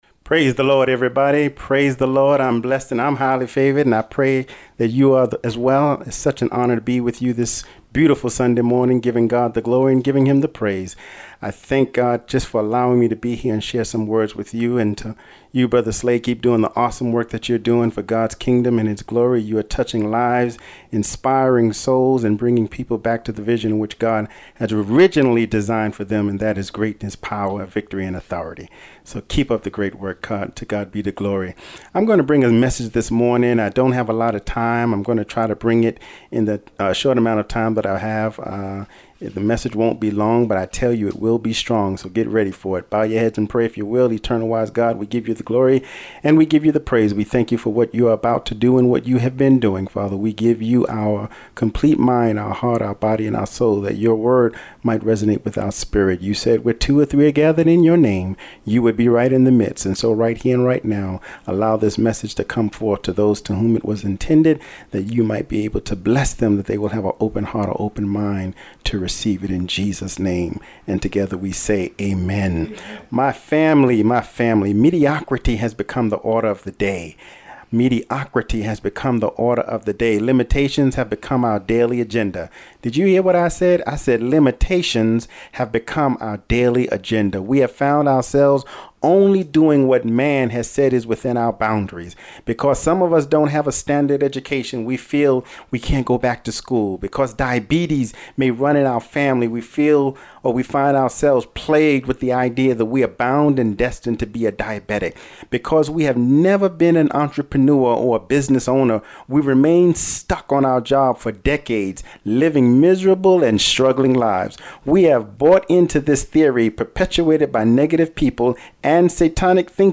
Black Gospel Music